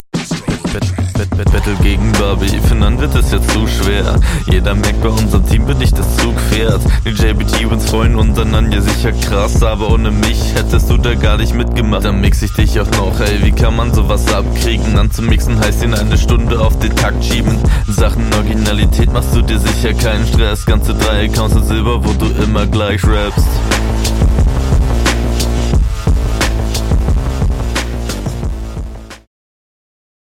Betonung mal anders, gefällt mir
Interessanter Stimmeinsatz. Flowlich nicht viel Variation, aber dafür alles im Takt.